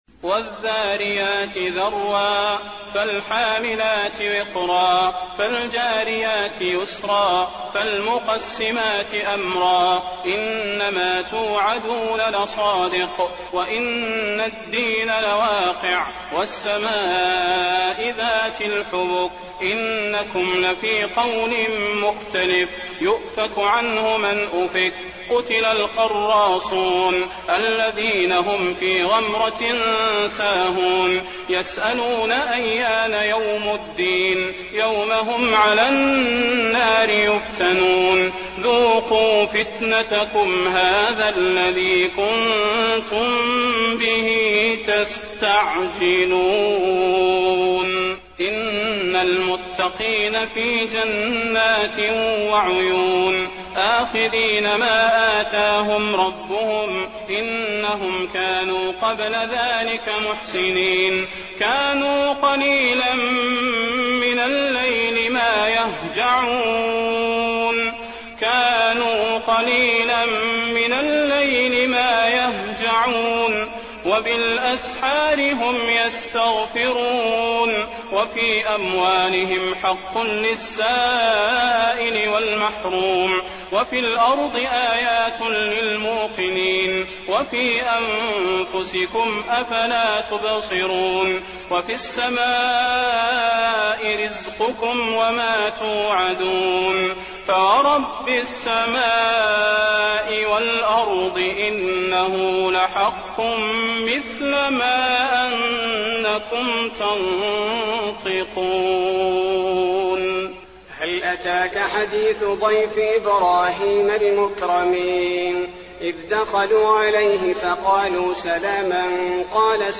المكان: المسجد النبوي الذاريات